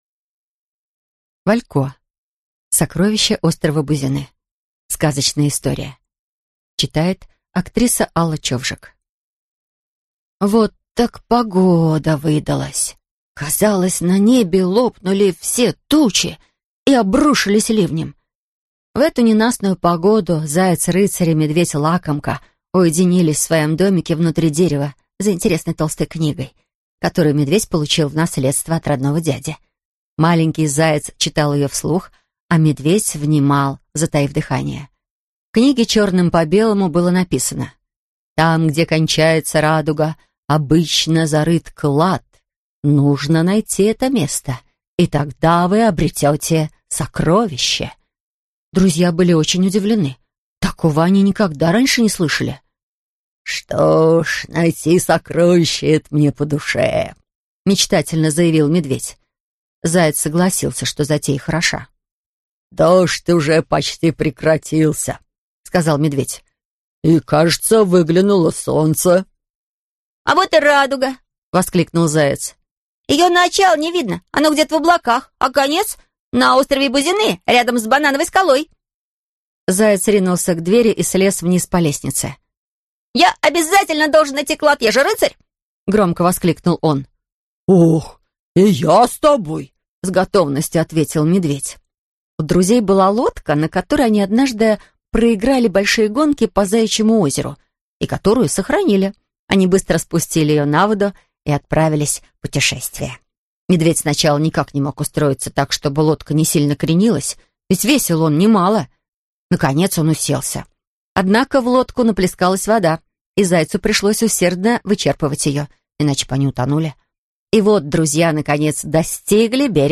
Аудиокнига Сокровище острова Бузины | Библиотека аудиокниг